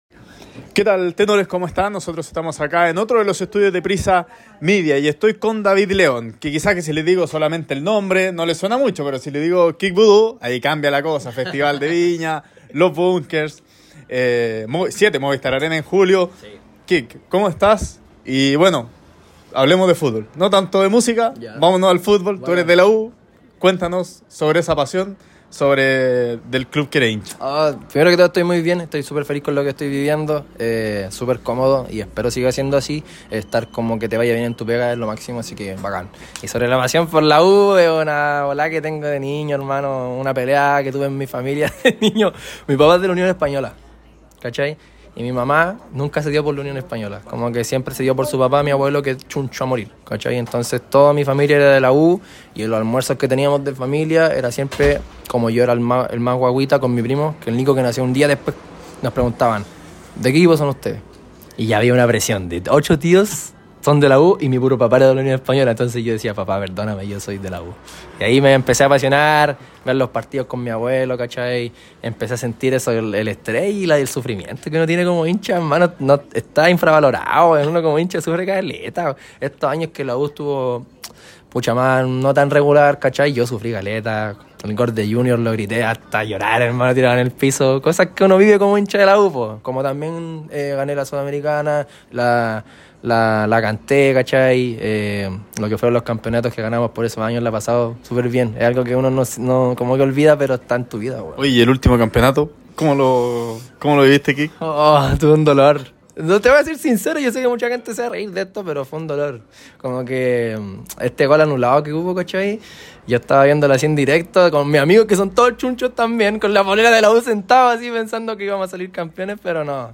Kid Voodoo, en conversación con ADN Deportes